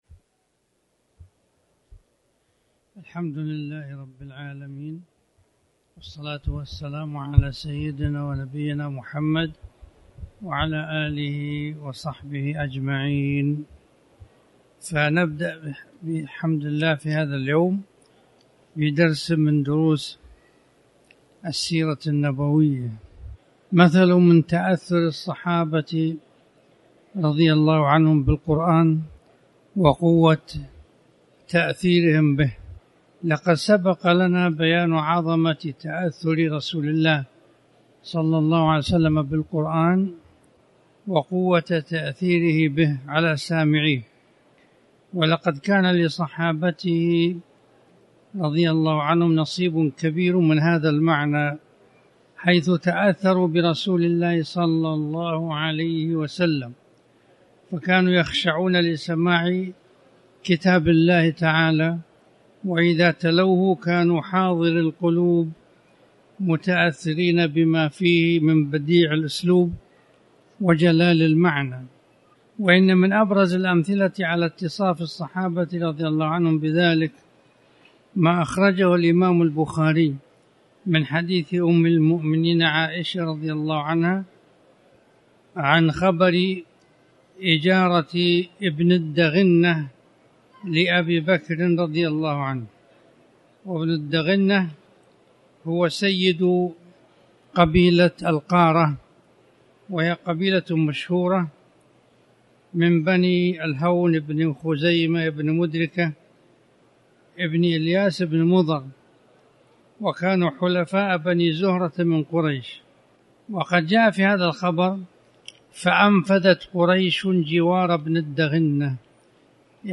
تاريخ النشر ٧ ذو القعدة ١٤٣٨ هـ المكان: المسجد الحرام الشيخ